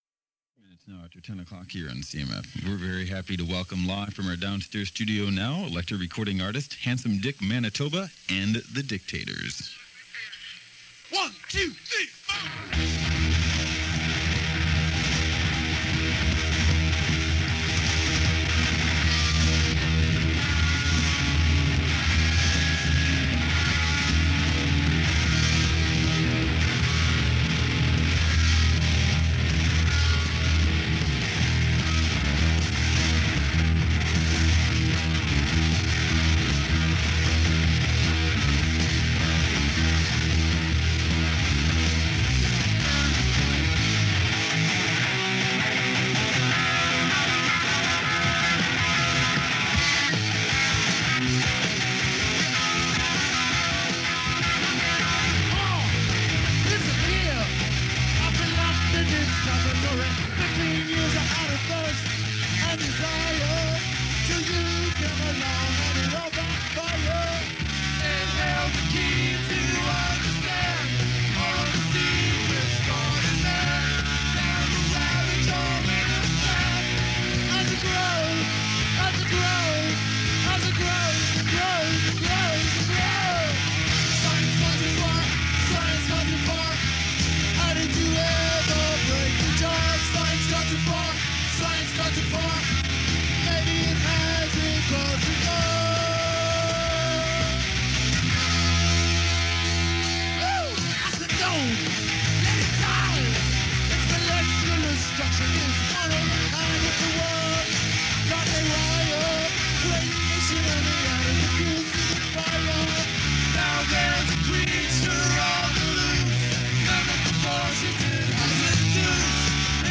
live in session
For aficionados of early Punk
Proto-Punk